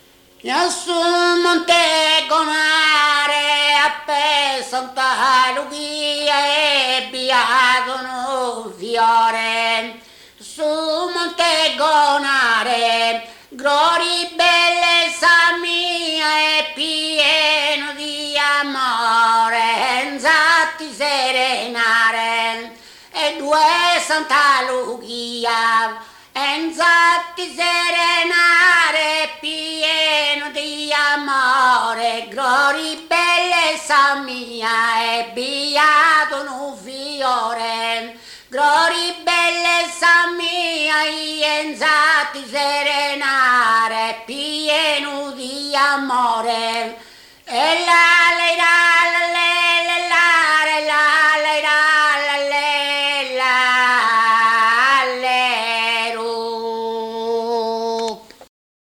ballu